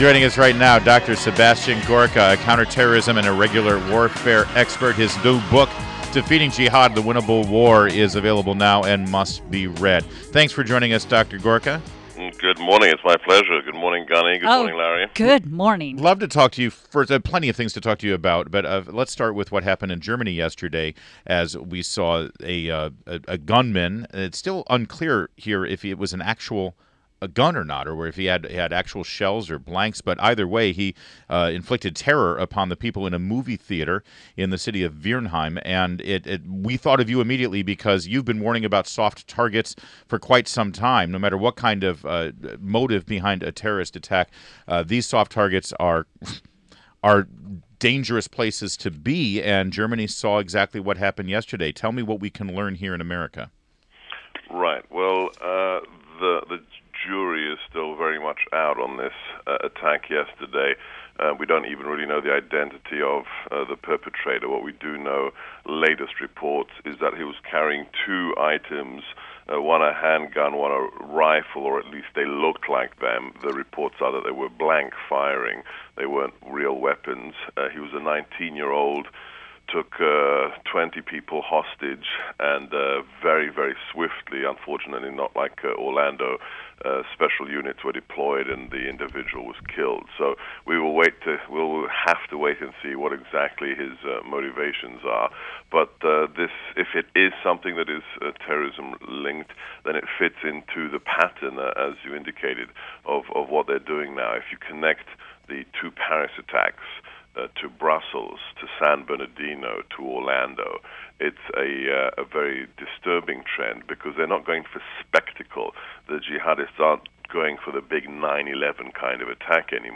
WMAL Interview - DR. SEBASTIAN GORKA - 06.24.16